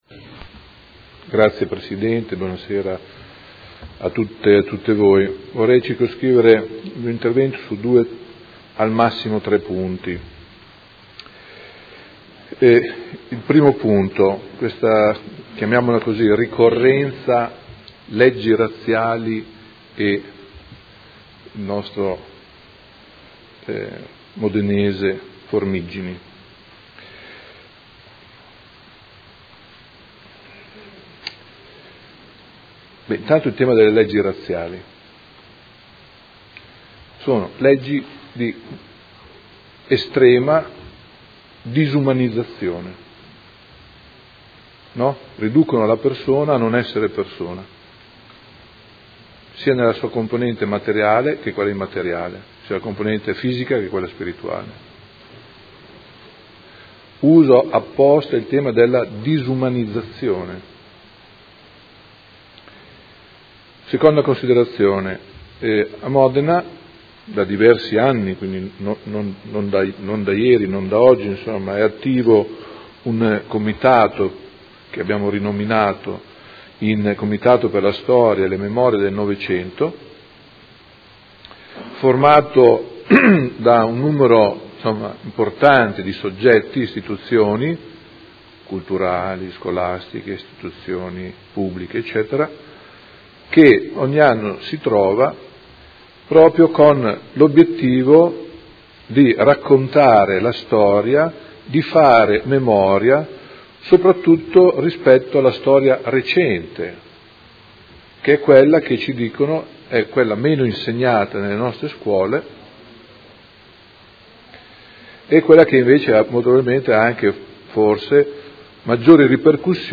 Gianpietro Cavazza — Sito Audio Consiglio Comunale